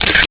Index of /phonetones/unzipped/LG/KU310/Default sounds
Shutter2.wav